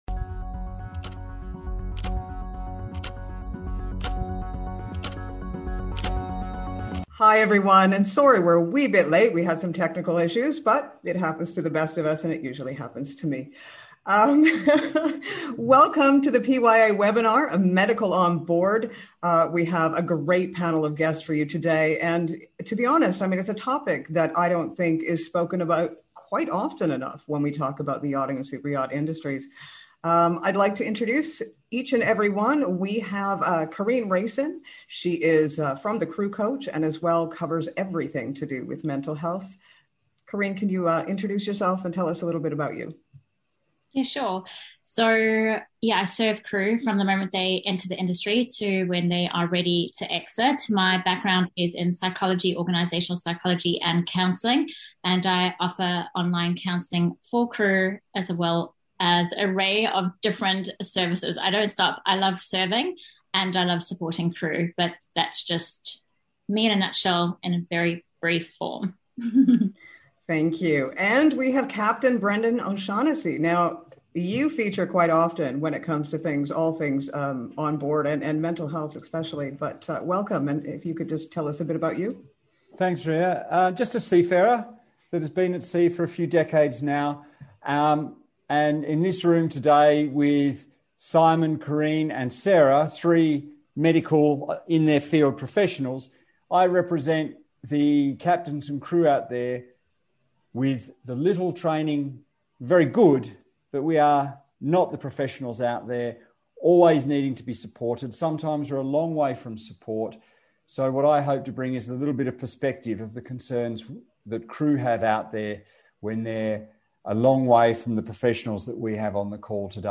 PYA WEBINAR: Medical On Board